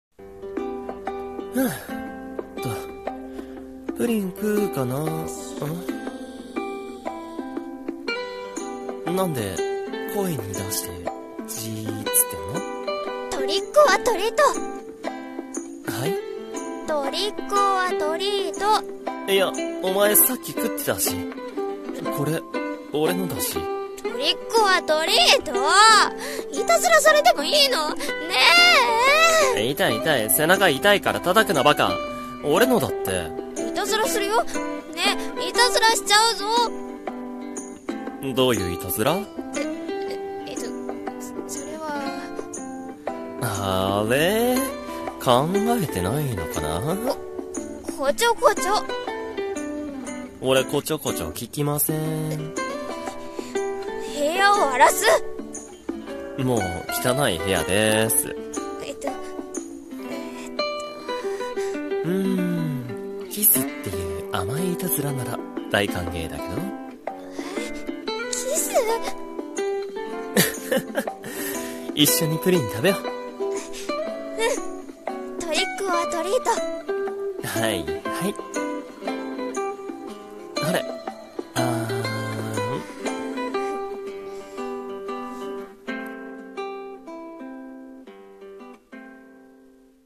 【声劇】トリック オア トリート